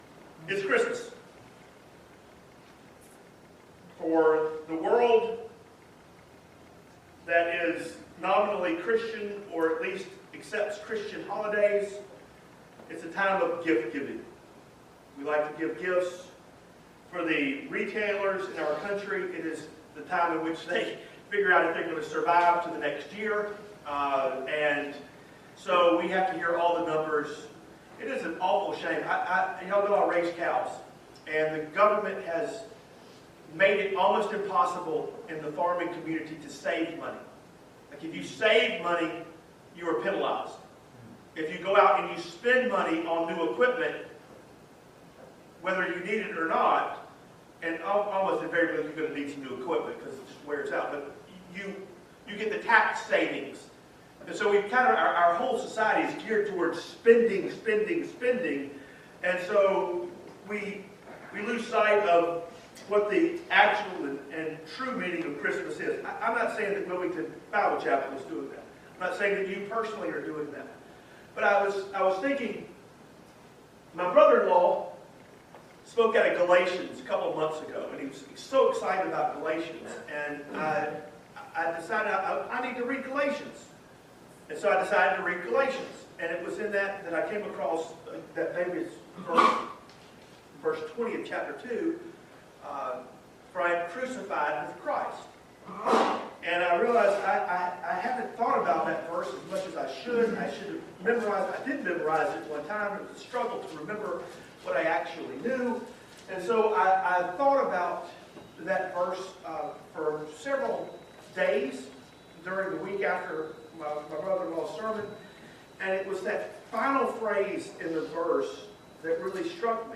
Galatians 2:20 Service Type: Family Bible Hour Jesus gave Himself for the world